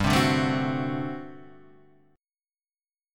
GM7sus2sus4 chord {3 3 0 2 1 2} chord